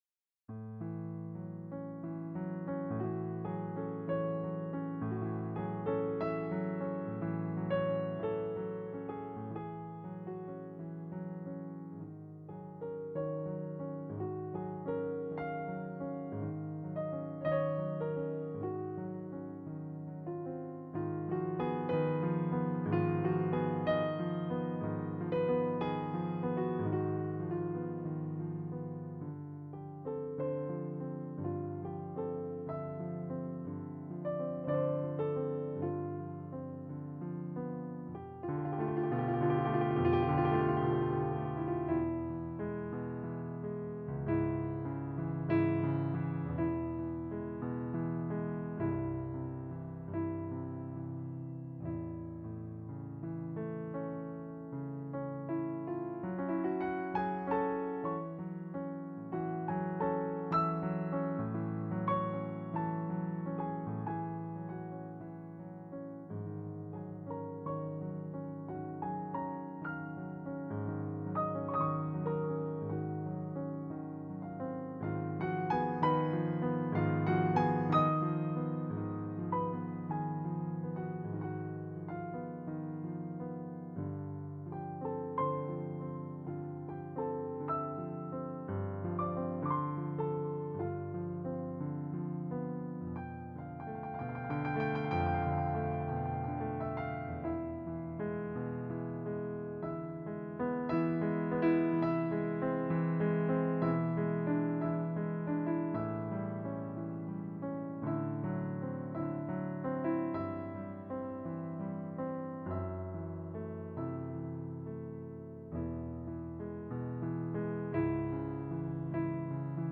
at his piano at home in 2024